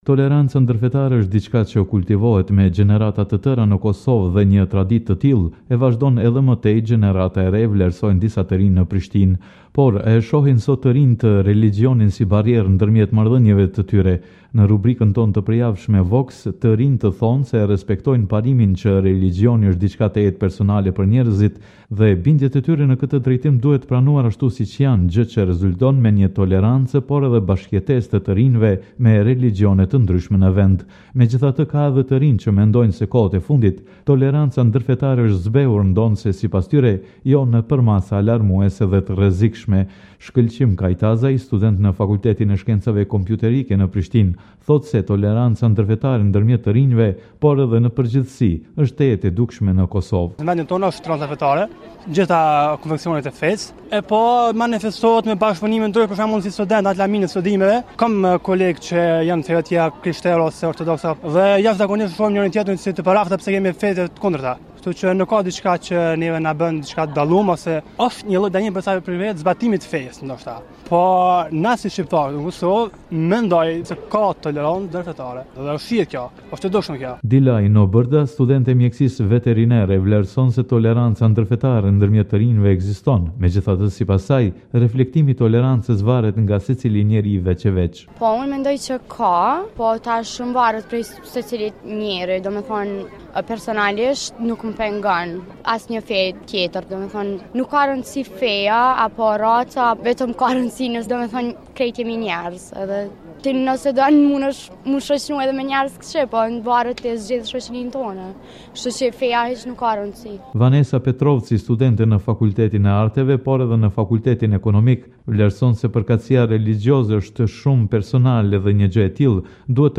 VOX me qytetarë